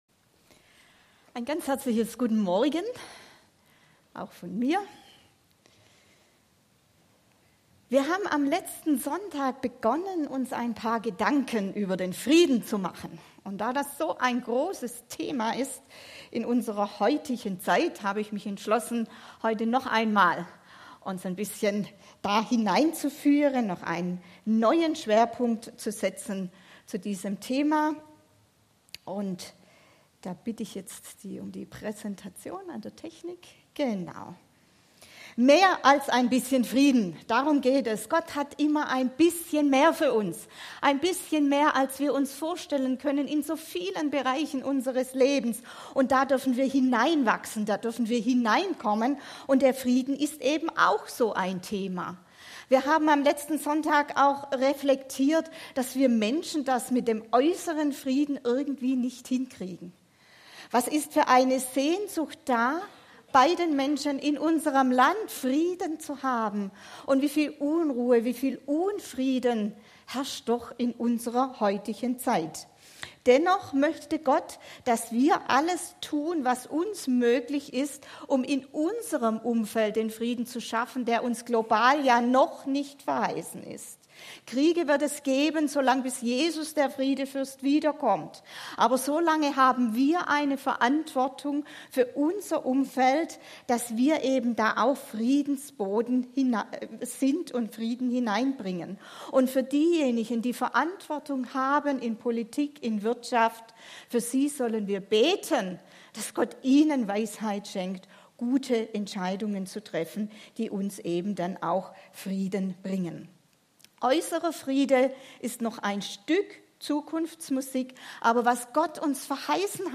Passage: Philipper 4, 8-9 Dienstart: Gottesdienst